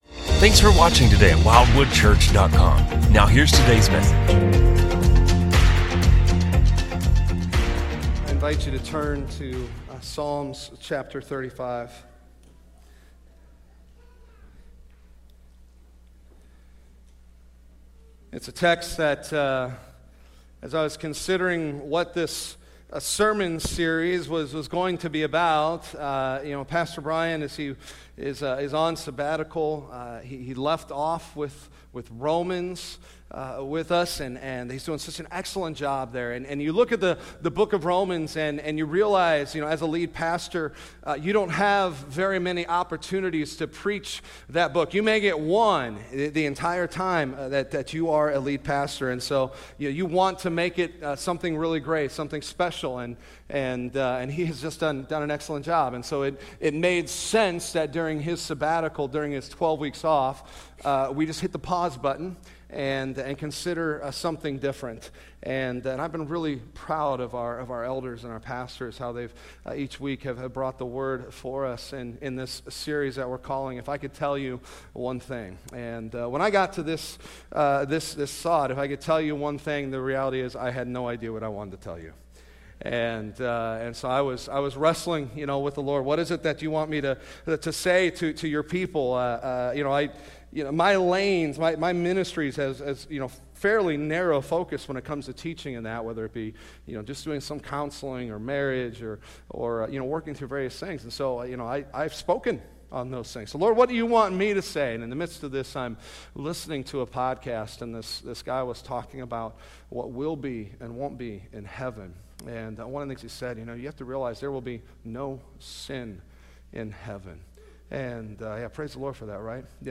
A message from the series "Wisdom From Above."